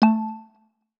FM Notification.wav